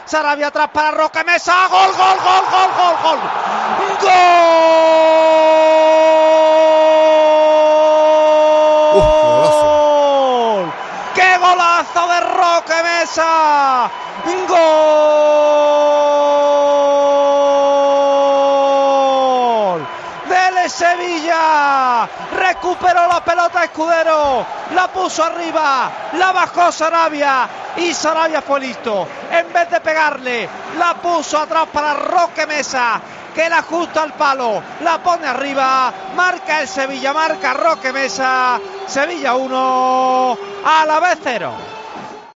Así sonaron los goles de Roque Mesa y Sarabia en el Sánchez-Pizjuán.